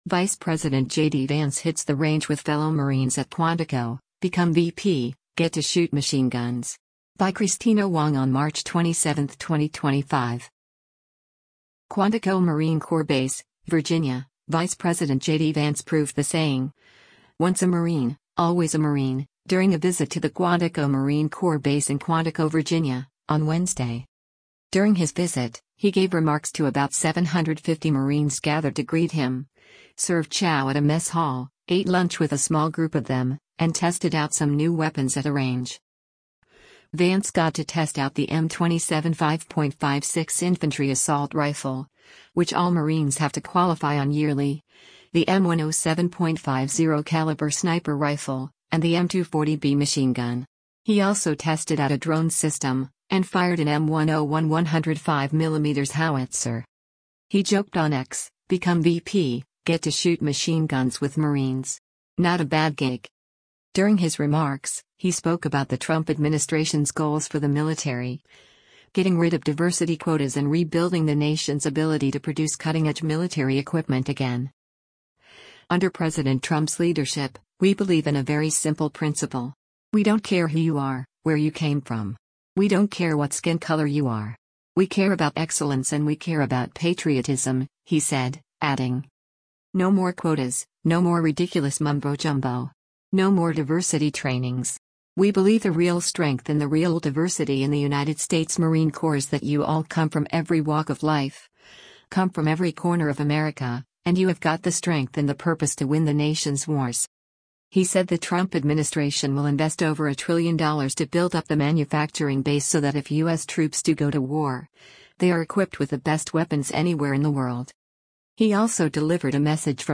During his visit, he gave remarks to about 750 Marines gathered to greet him, served chow at a mess hall, ate lunch with a small group of them, and tested out some new weapons at a range.